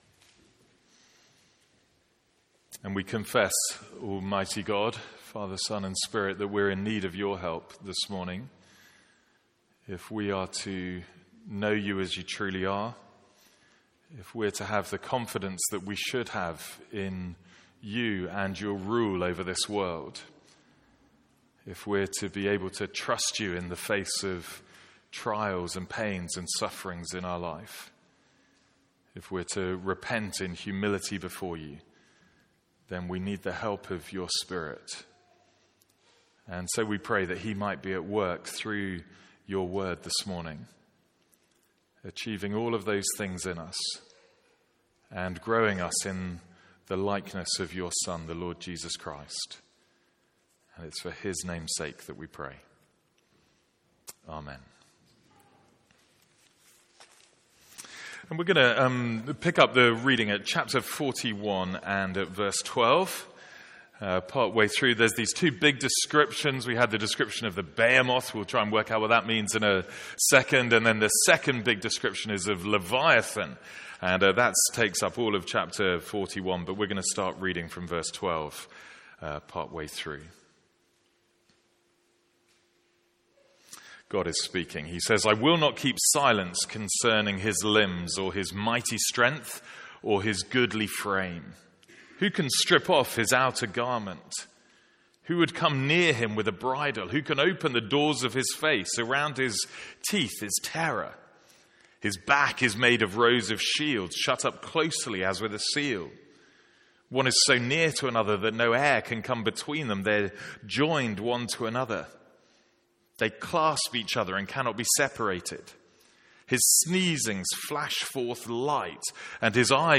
Sermons | St Andrews Free Church
From our morning series in Job.